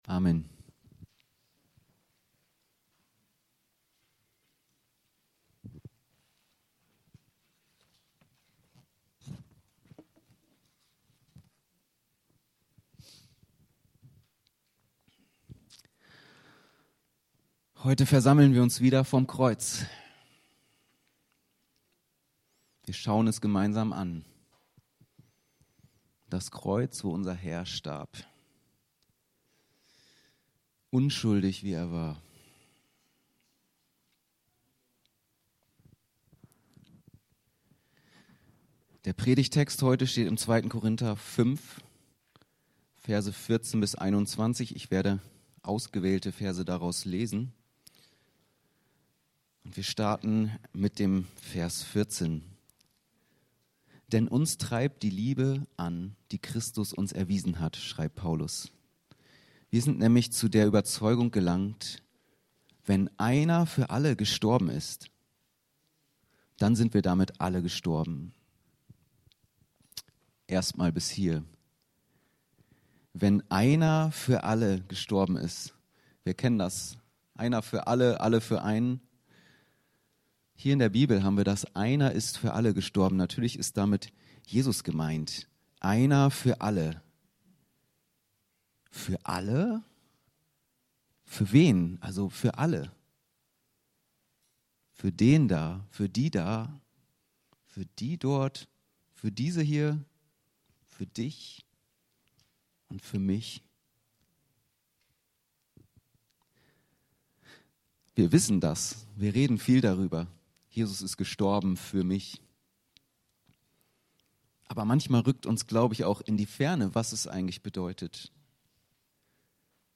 Das wollen wir in dieser Predigt erschließen.